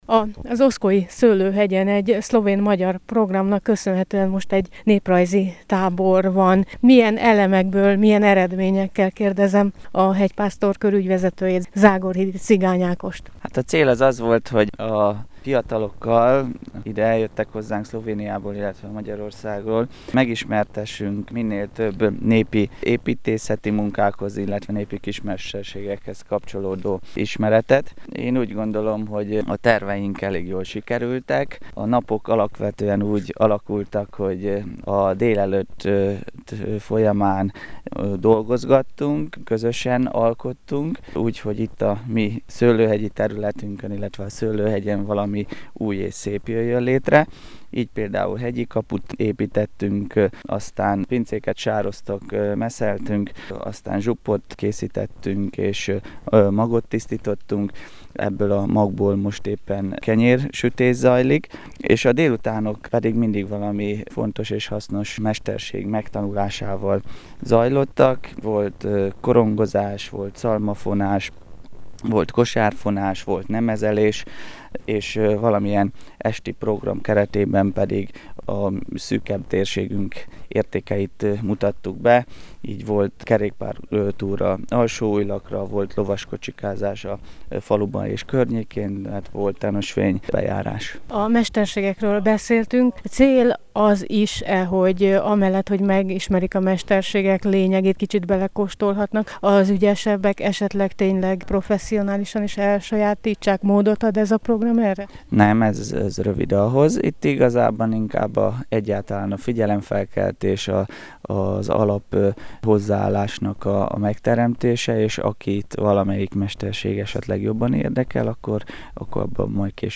A táborban készült rádióriport, mely elhangzott a Rádió8 Körmend műsorán és a Isis Rádió Vasi Mozaik című magazinjában: